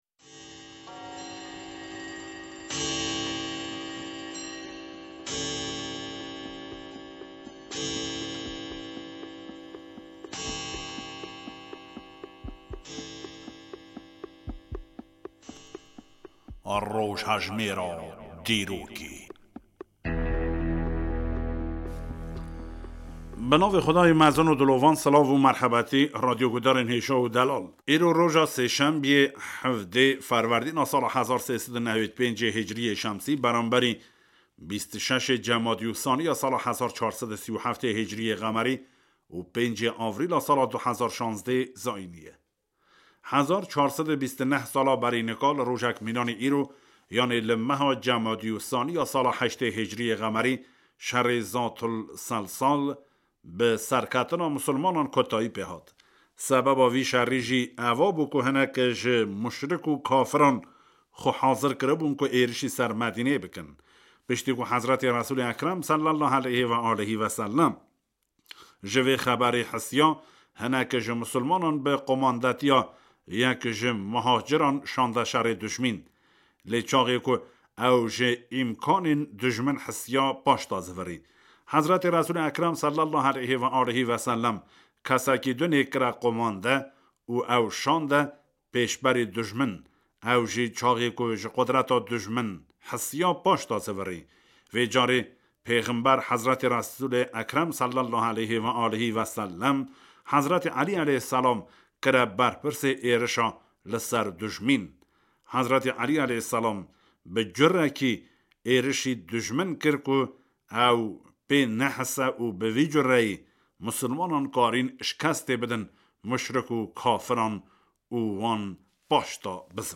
Bernameyeke 5 xulekî ye ku hemî rojan ji saet 18:53 heta 18:58'ê ji Radyoya Kurdî ya Têhranê tê weşandin. Di vê bernameyê da bûyerên salên buhurî (tavî, mîlad, mehî) tên hilkolandin.